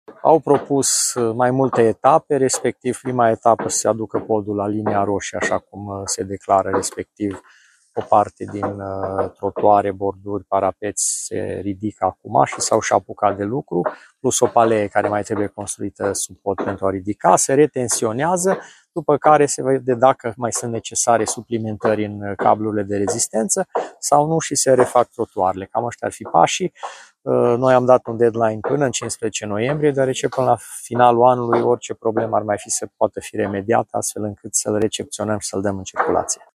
Podul hobanat construit la Arad, peste râul Mureș, este sigur pentru circulație, relevă un raport prezentat de primarul Călin Bibarț.